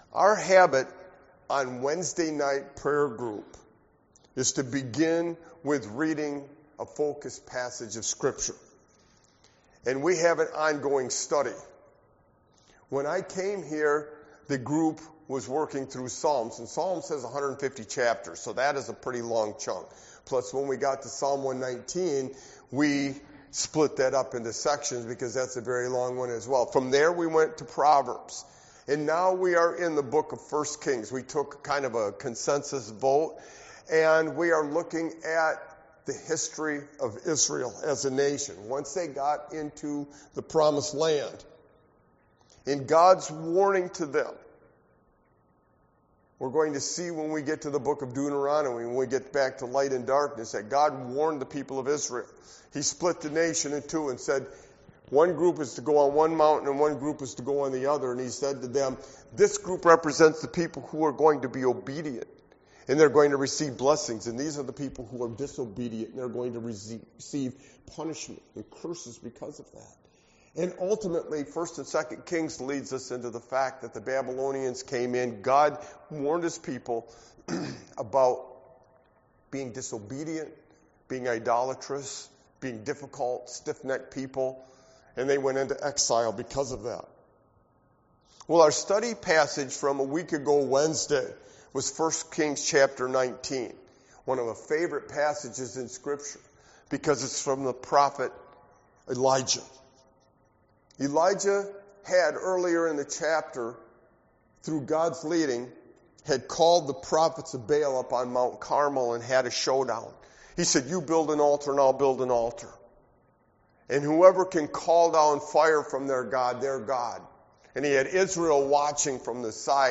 Sermon-Why-as-Sovereign-God-is-crucial-VII-3622.mp3